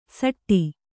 ♪ saṭṭi